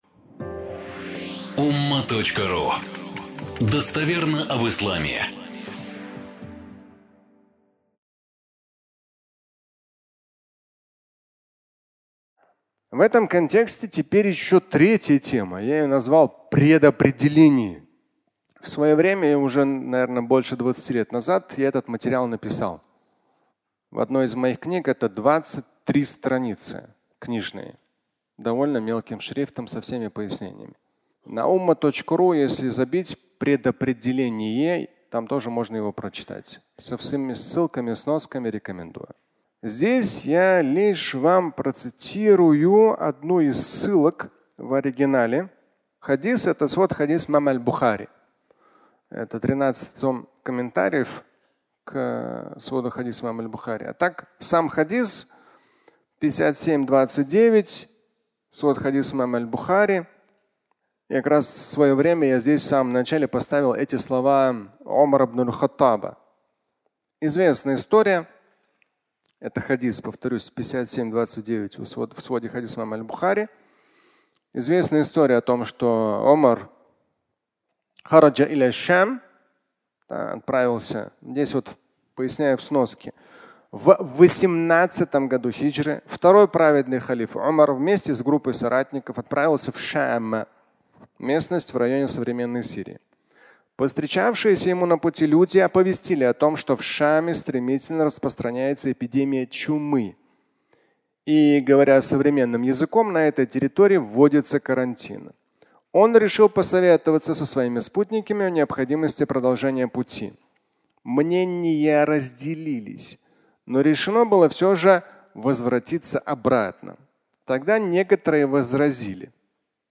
"Предопределение" (аудиолекция)
Пятничная проповедь